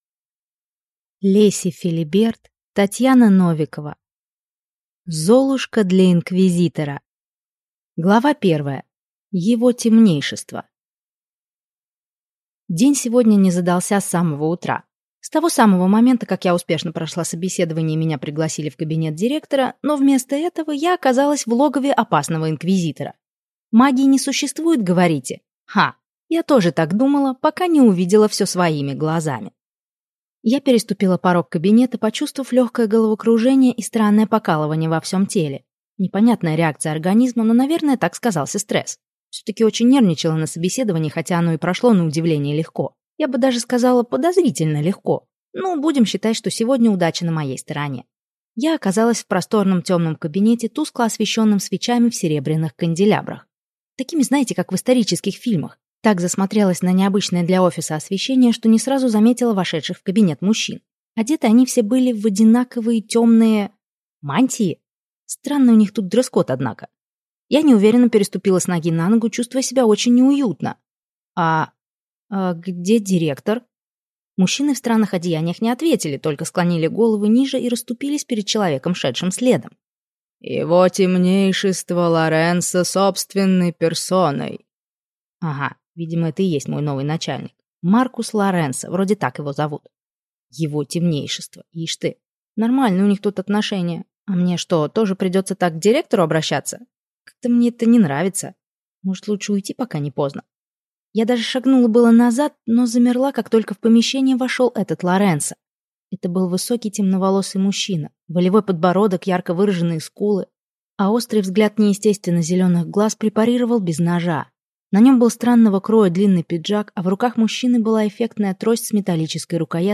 Аудиокнига Золушка для инквизитора | Библиотека аудиокниг
Прослушать и бесплатно скачать фрагмент аудиокниги